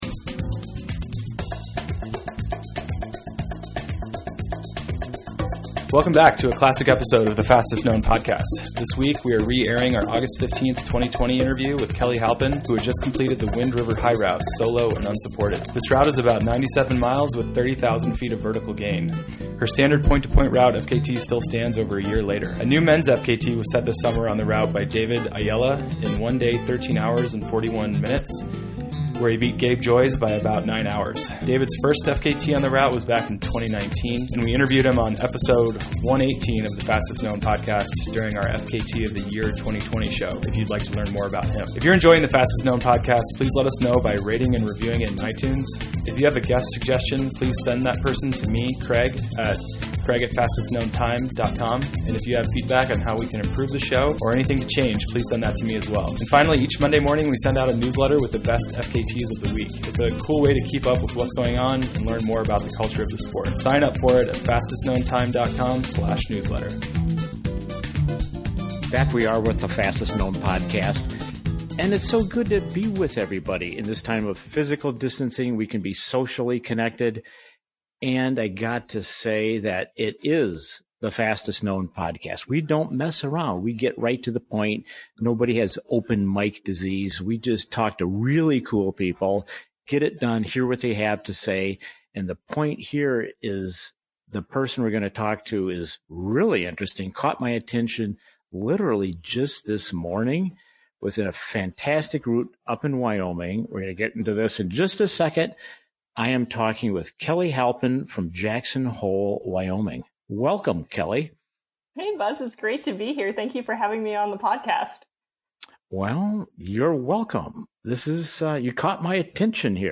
Log in or register to post comments Category Person-Person Welcome back to a classic episode of the Fastest Known Podcast.